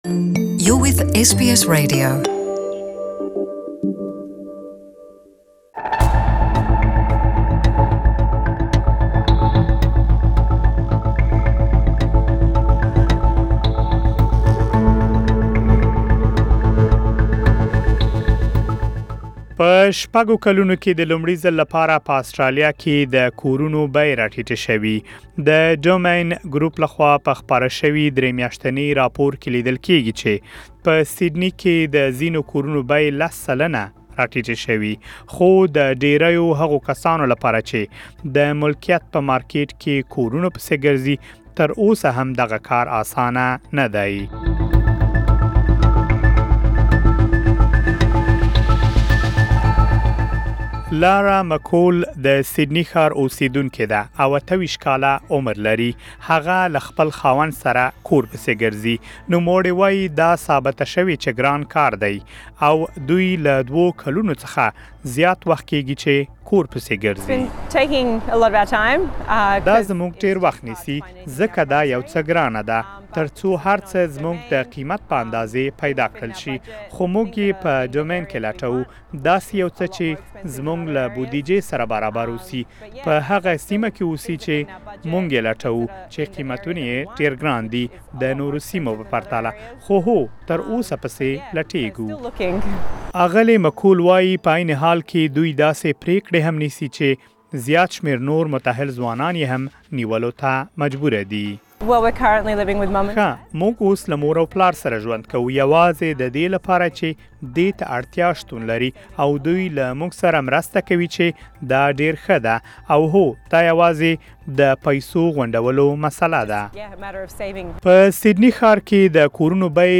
For more details, please listen to the full report in Pashto.